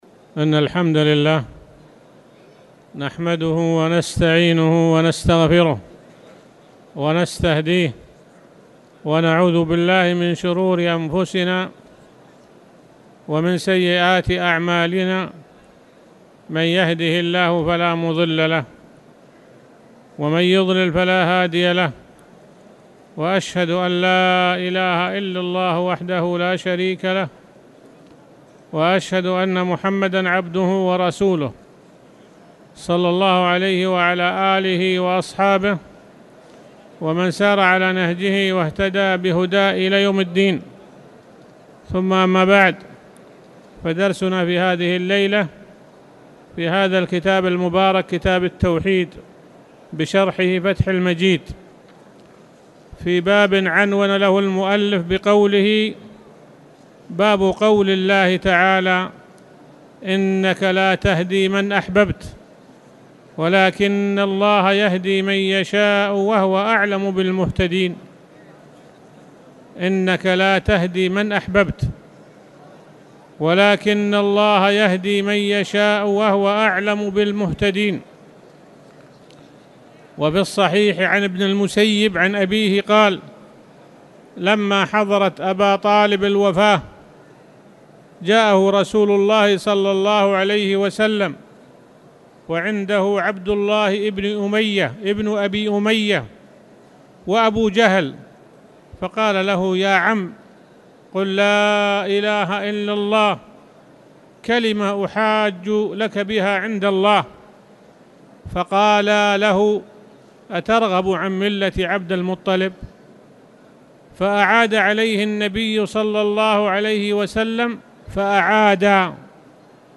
تاريخ النشر ١٩ ربيع الثاني ١٤٣٨ هـ المكان: المسجد الحرام الشيخ